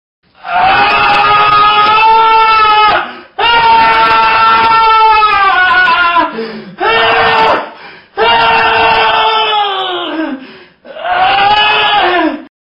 Звуки крика людей
Где медведь орет АААААААААААААААА